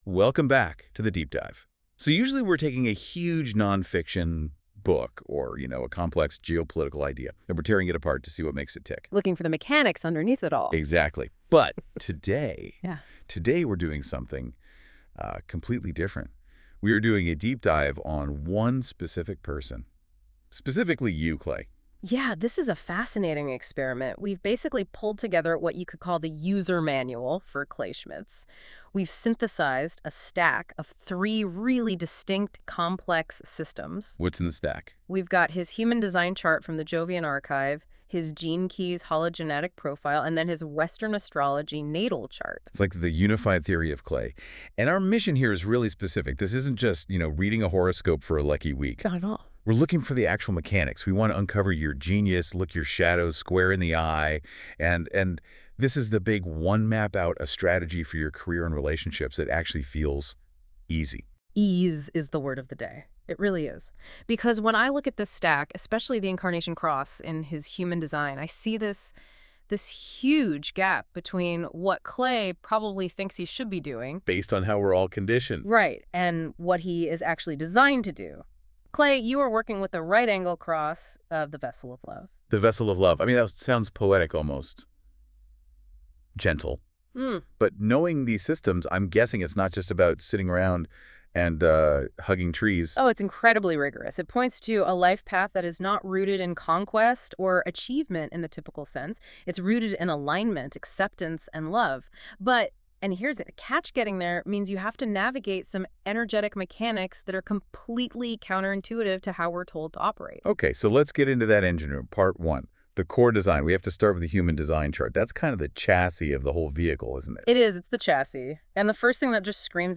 A Personalized Audio Reading + Visual Blueprint
Important Note These audio and graphics are AI-generated interpretations based on your birth data and system inputs.